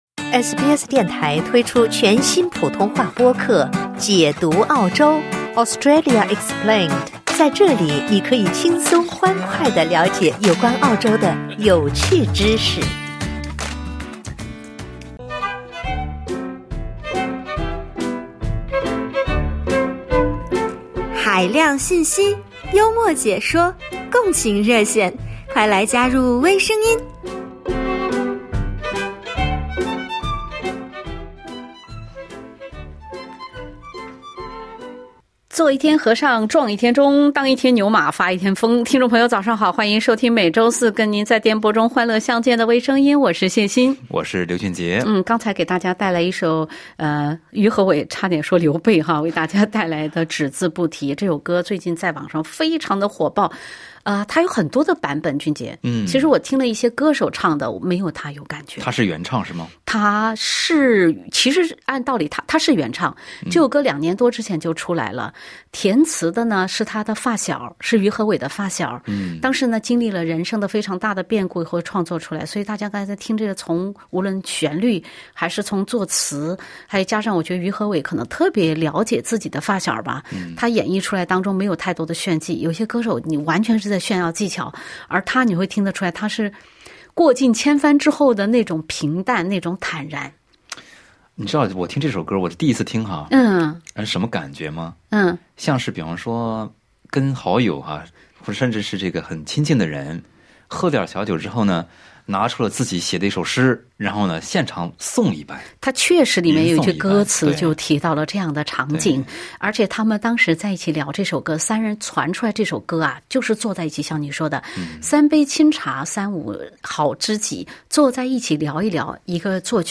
热心听众参与热线分享，如果有机会增强自己的身体，甚至‘升级’自己的器官，他们会选择哪些？会不会觉得自己“升级”后的身体好陌生？